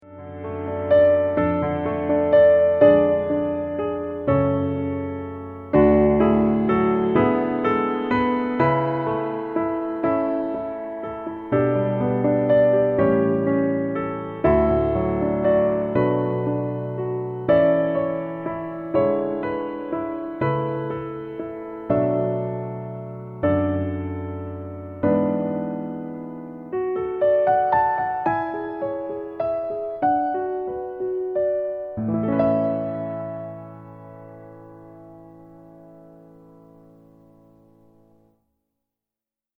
Piano - Low